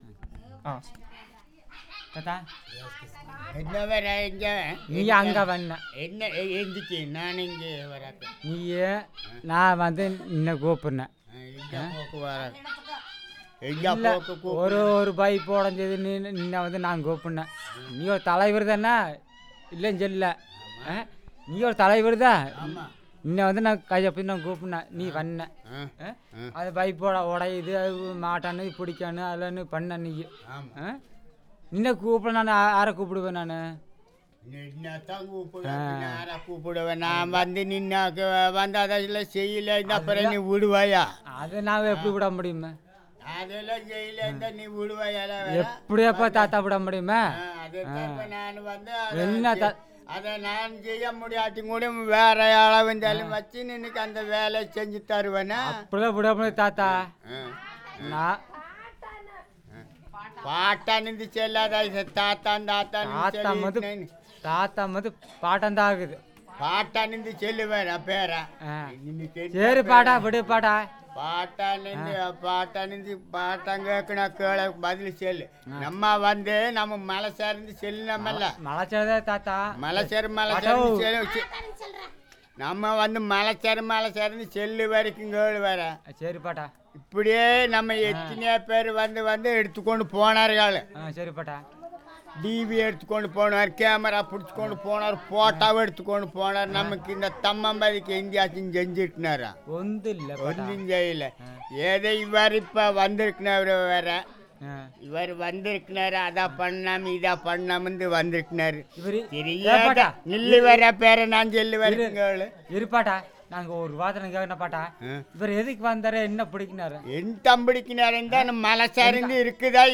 Conversation about the community
It is a staged conversation for the purpose of recording. The informants talk about how none of the previous documentors have brought any good to the community. they are hopeful that this time the community will be known outside.